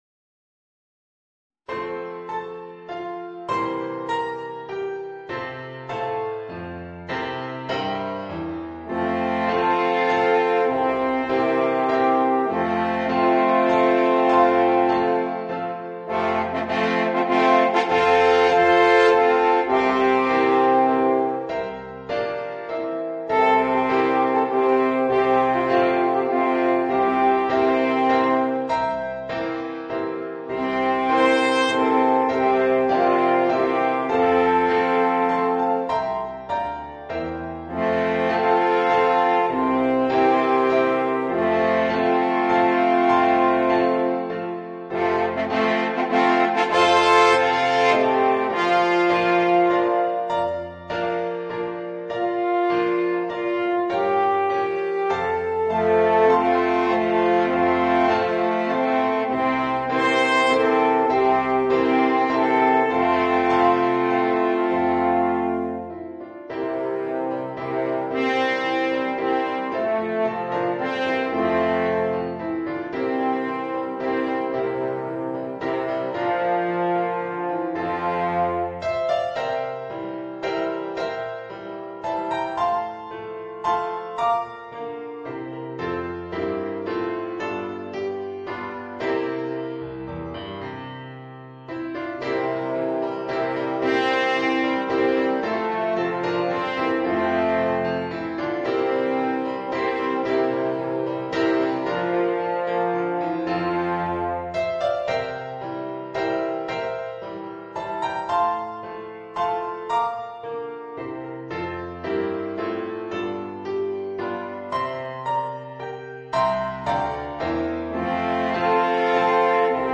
Voicing: 3 Alphorns and Piano